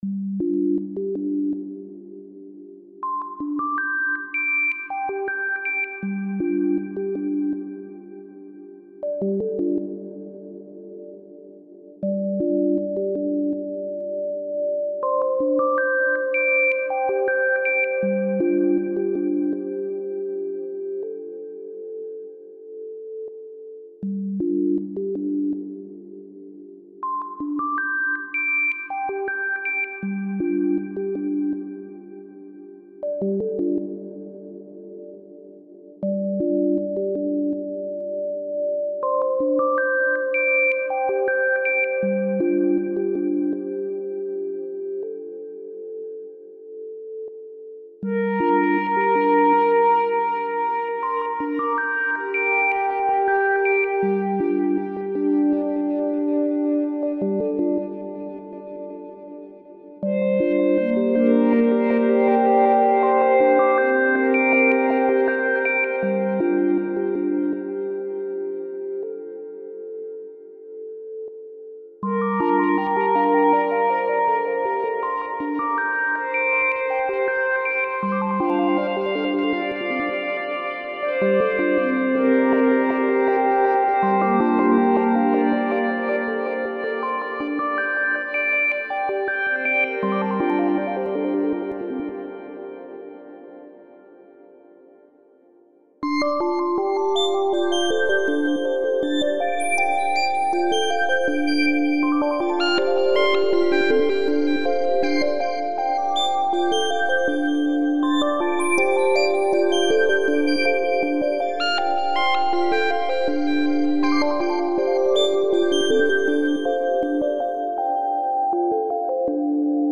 Tagged as: Ambient, Electronica